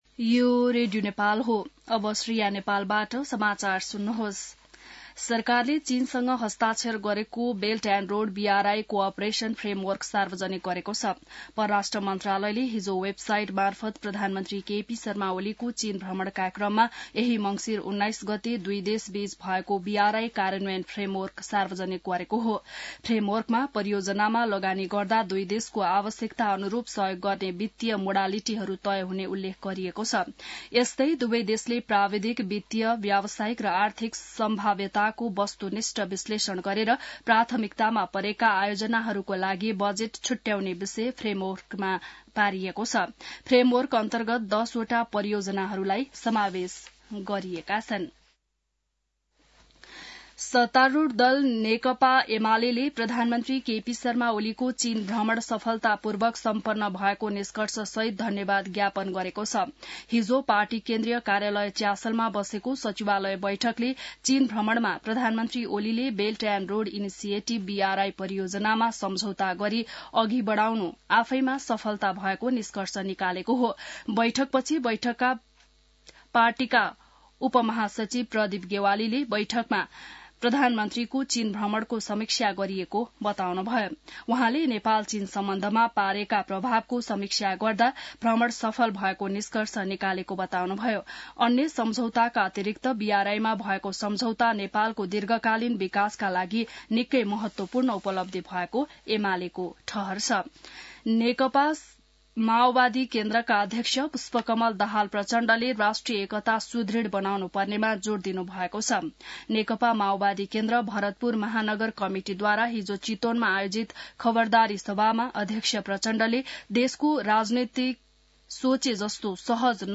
बिहान ६ बजेको नेपाली समाचार : २७ मंसिर , २०८१